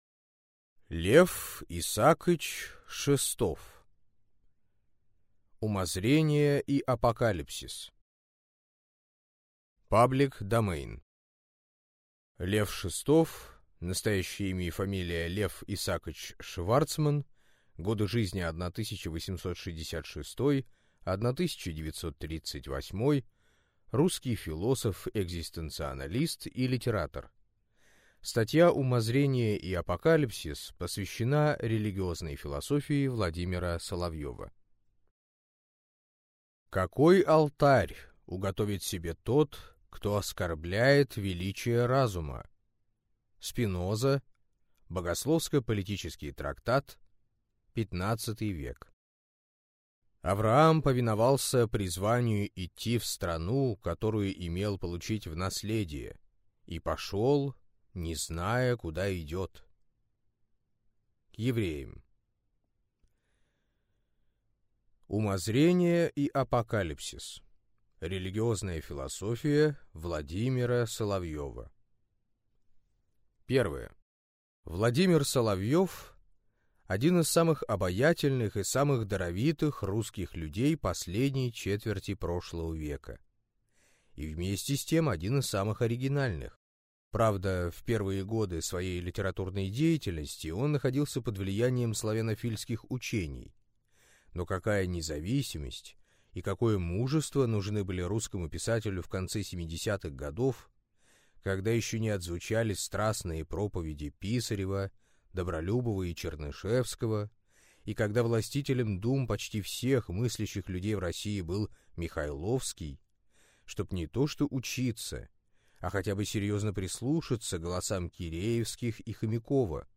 Аудиокнига Умозрение и Апокалипсис | Библиотека аудиокниг